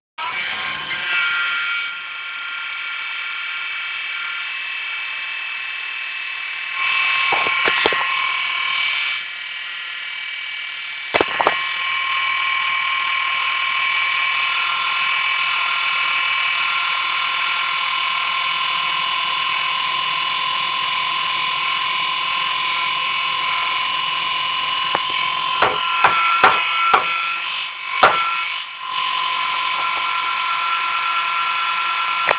При включении компьютера громкий и не приятный звук., Как это исправить?сейчас аудиозапись выложу
Думаю ваш кулер стал сильно шуметь.